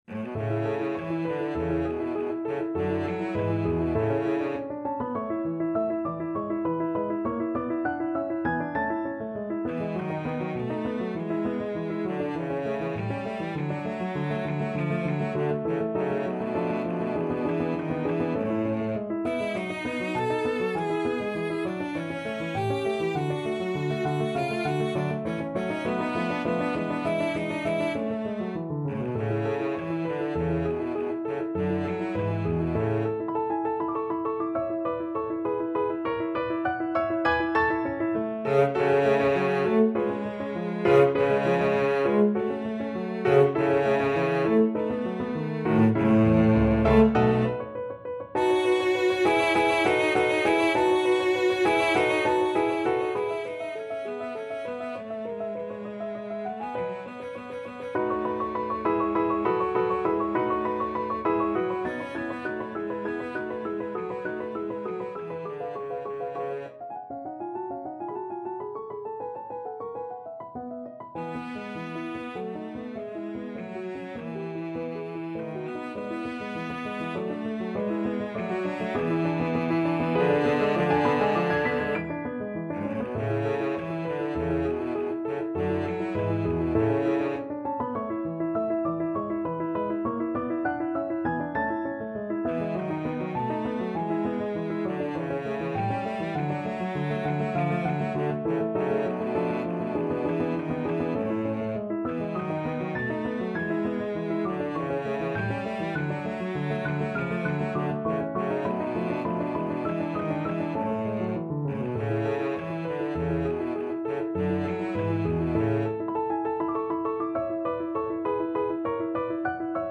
Cello version
2/4 (View more 2/4 Music)
Nicht zu geschwind und sehr singbar vorgetragen
F#3-B5
Classical (View more Classical Cello Music)